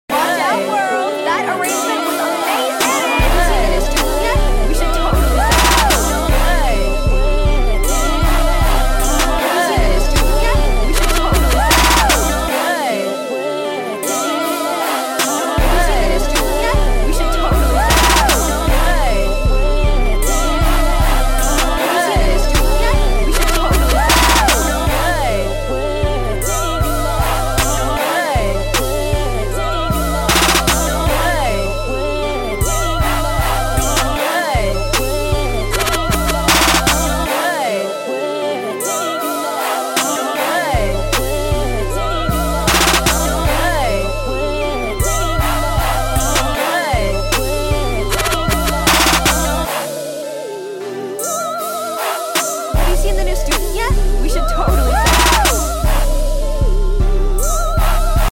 This the Y2K sound